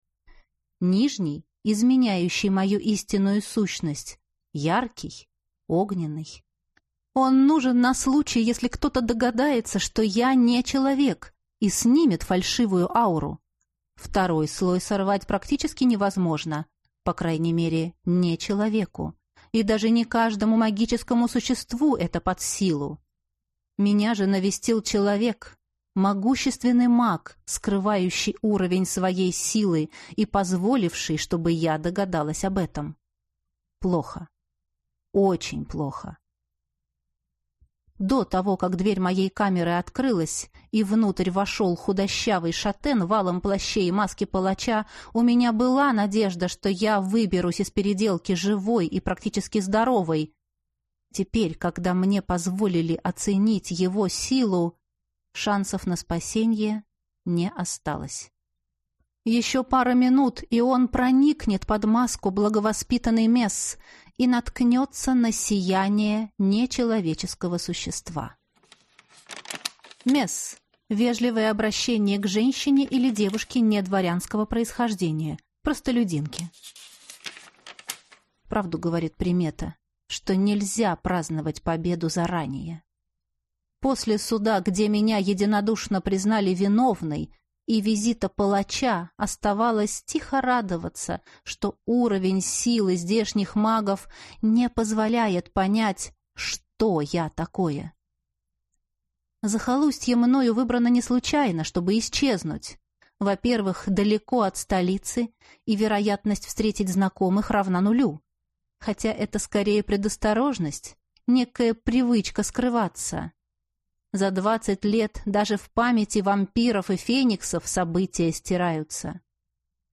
Аудиокнига Секретарь палача | Библиотека аудиокниг